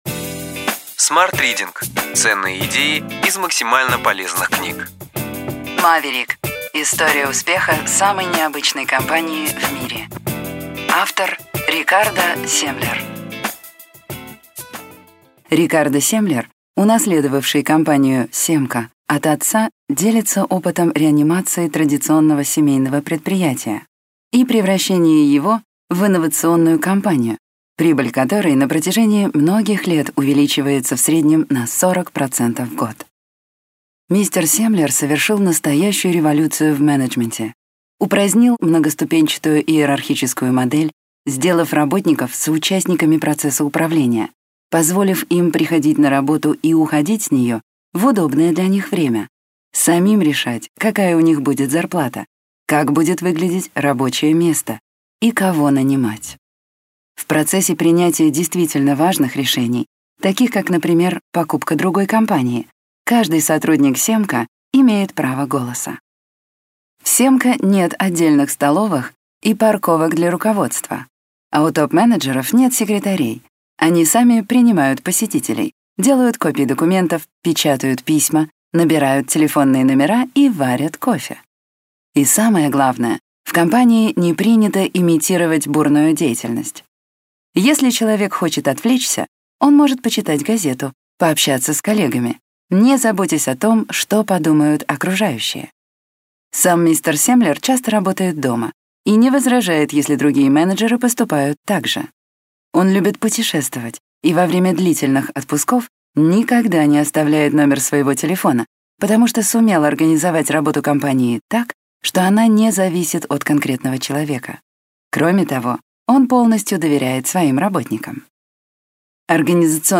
Аудиокнига Ключевые идеи книги: Маверик. История успеха самой необычной компании в мире.